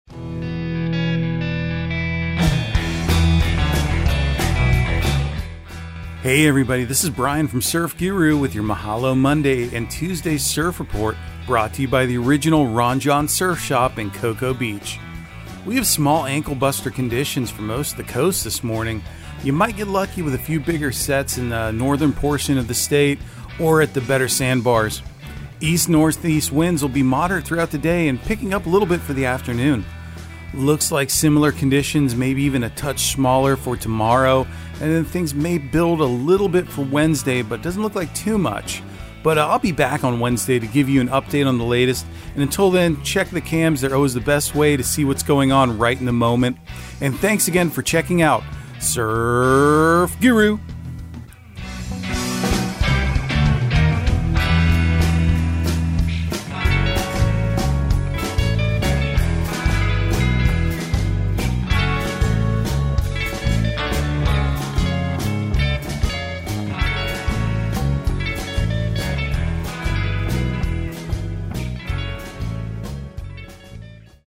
Surf Guru Surf Report and Forecast 10/10/2022 Audio surf report and surf forecast on October 10 for Central Florida and the Southeast.